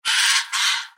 دانلود صدای پرنده 9 از ساعد نیوز با لینک مستقیم و کیفیت بالا
جلوه های صوتی
برچسب: دانلود آهنگ های افکت صوتی انسان و موجودات زنده دانلود آلبوم صدای پرندگان از افکت صوتی انسان و موجودات زنده